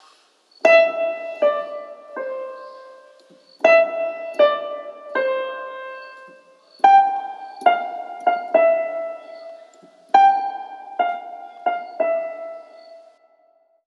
Tags: theater